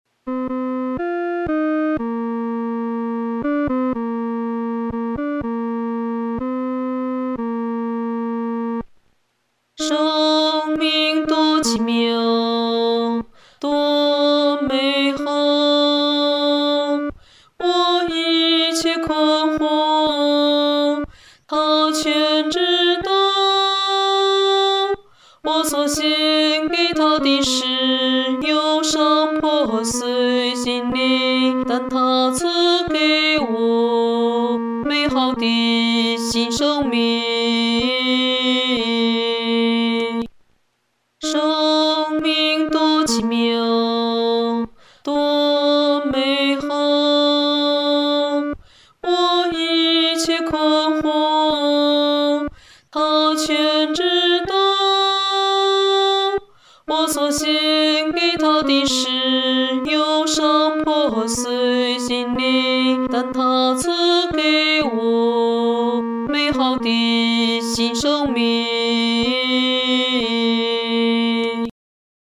女低